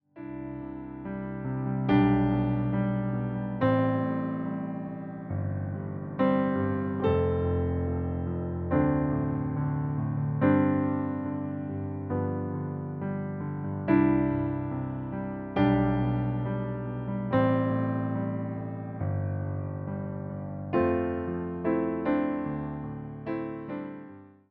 Wersja demonstracyjna:
70 BPM
C – dur